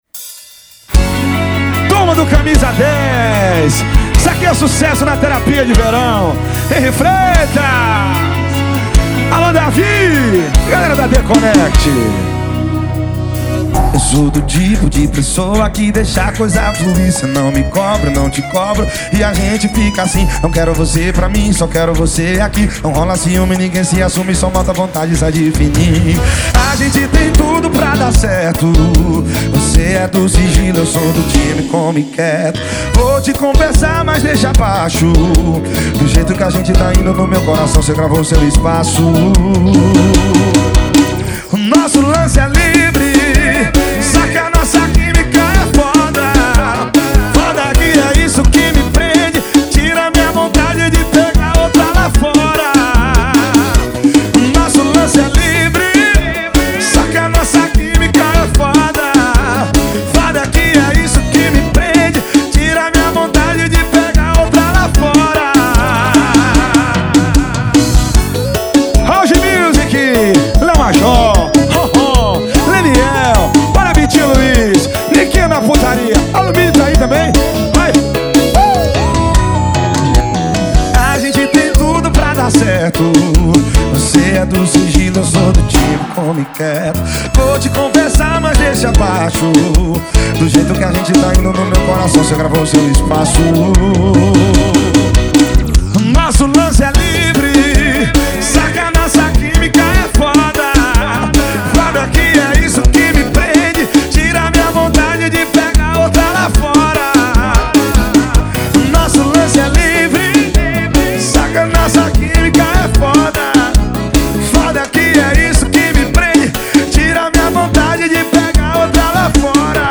2024-02-14 18:31:12 Gênero: Forró Views